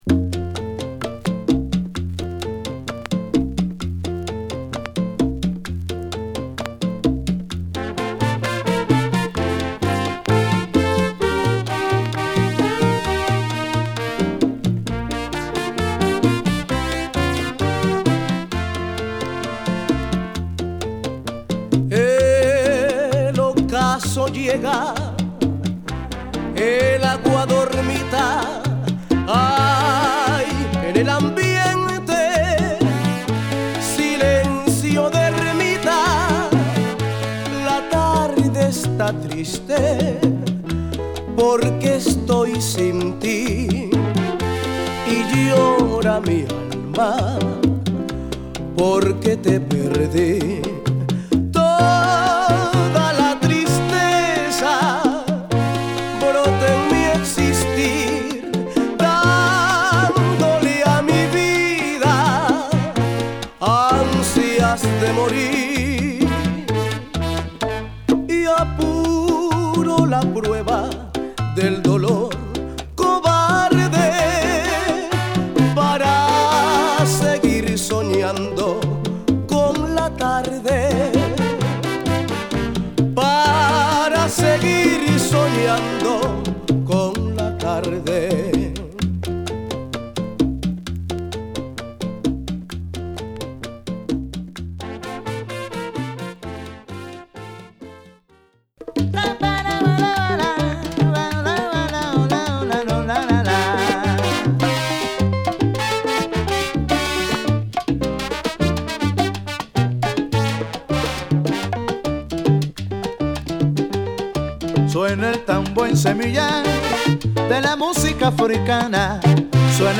ベネズエラの名門サルサ楽団